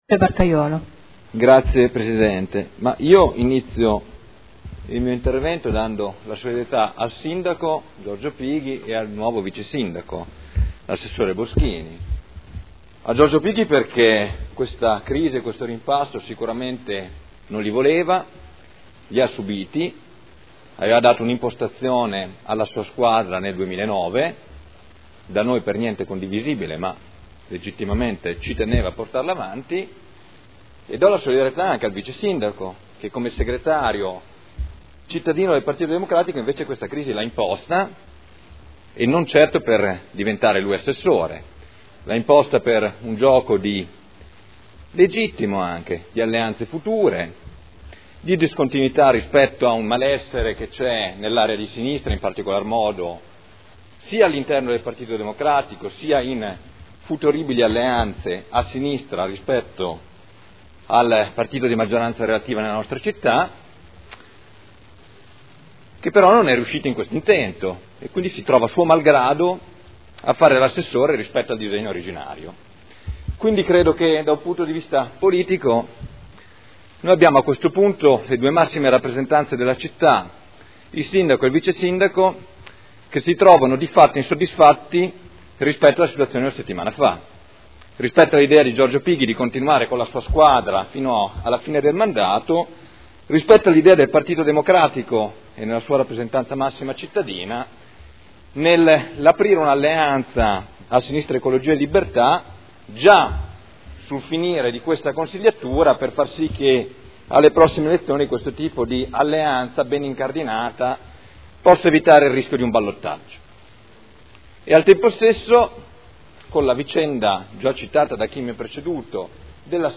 Seduta del 23/04/2012. Dibattito su comunicazione del Sindaco sulla composizione della Giunta.